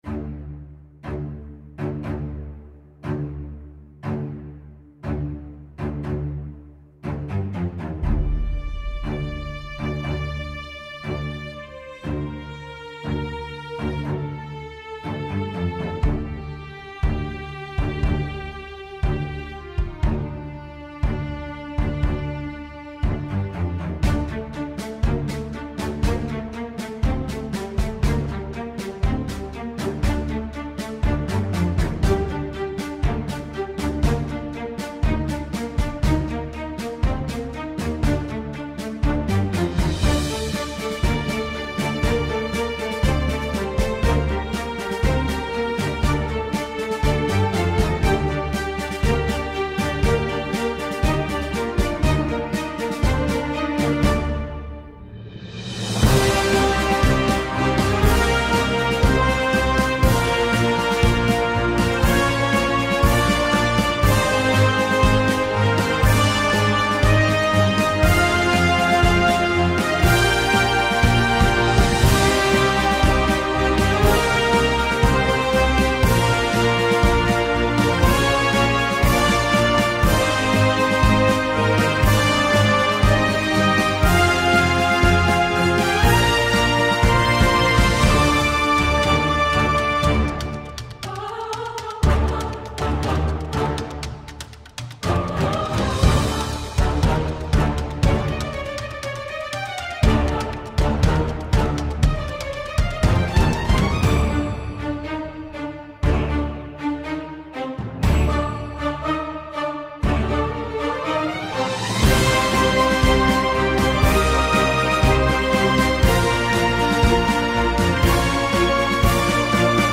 ダークで英雄的なファンタジー・オーケストラ、重厚な太鼓のパーカッション、高らかに響くバイオリン、荘厳なホルン、神秘的で幻想的な合唱、壮大な映画のようなサウンドスケープ、テンポ110bpm
重厚なストリングスとコーラスが響く、王道バトルアンセム。